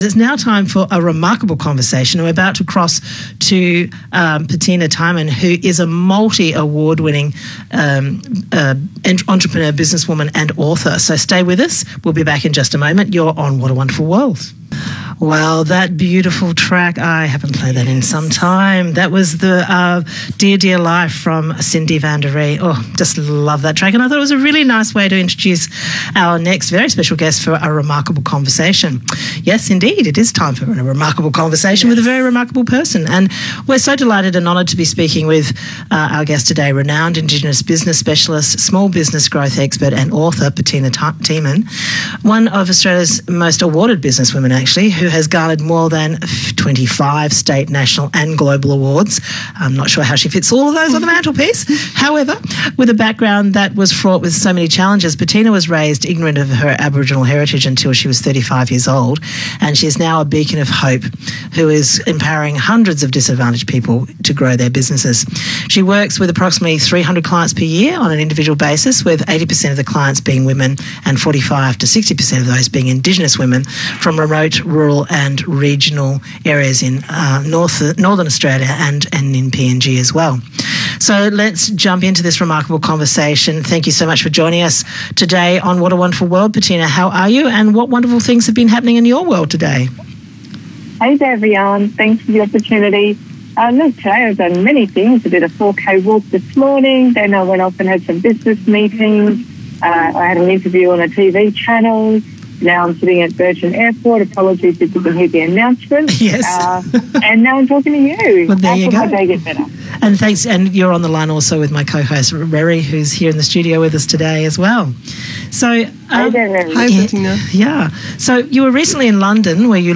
Radio-Interview-Cut.m4a